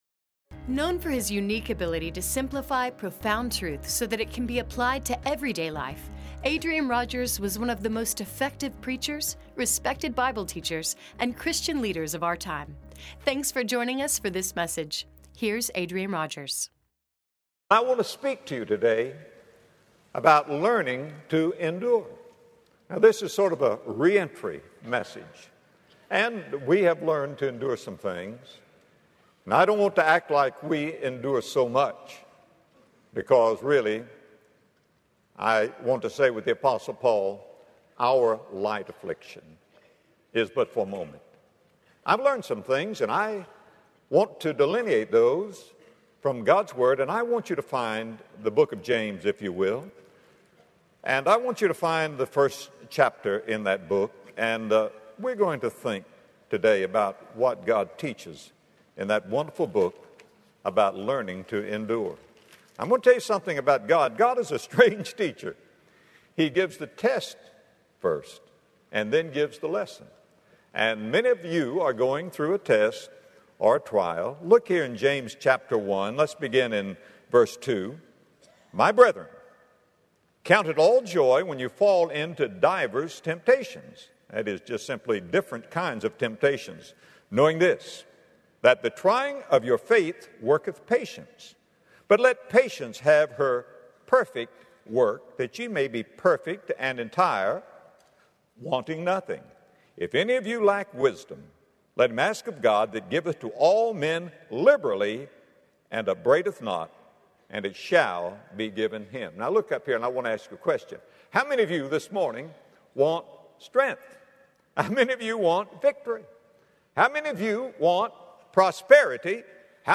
But in this message, Adrian Rogers explains the importance of learning to endure, as well as five extraordinary things we can learn from the trials we face.